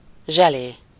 Ääntäminen
Synonyymit blanc-manger Ääntäminen France: IPA: [ʒə.le] Haettu sana löytyi näillä lähdekielillä: ranska Käännös Ääninäyte 1. zselé Suku: f .